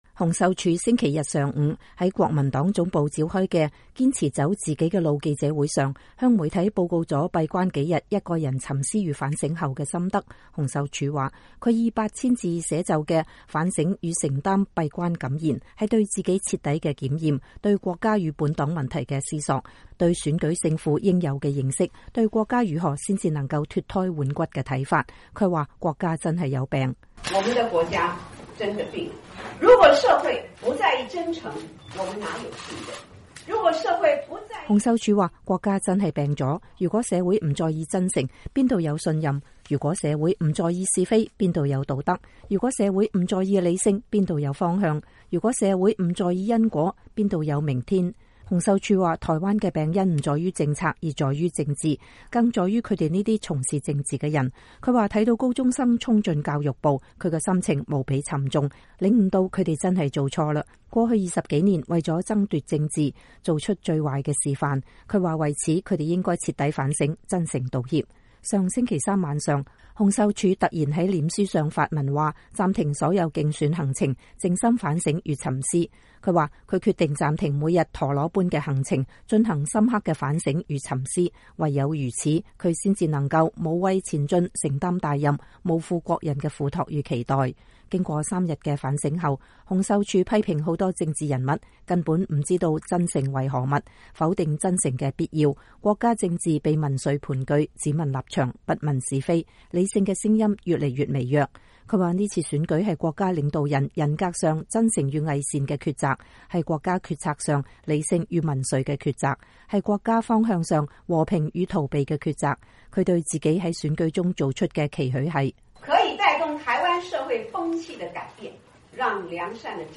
洪秀柱星期天上午在國民黨總部召開的“堅持走自己的路”記者會上，向傳媒報告了閉關幾天一個人沉思與反省後的心得。